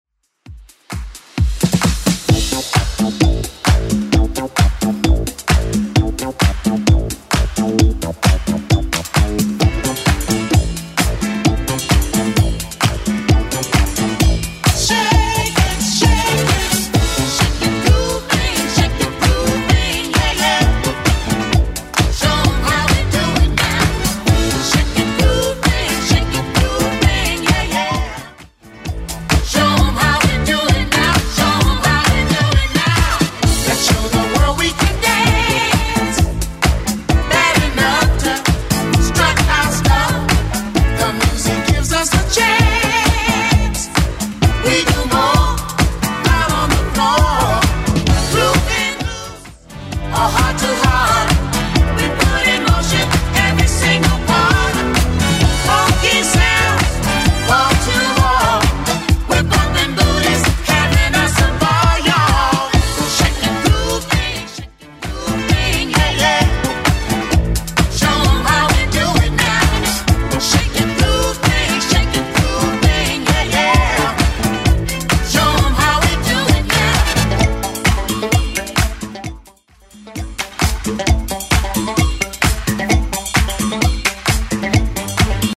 Genre: 80's
BPM: 112